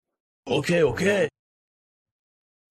Exhausted Girl Says Okay sound effect Okay sound effect
Thể loại: Âm thanh meme Việt Nam
ok-ok-sound-effect-www_tiengdong_com.mp3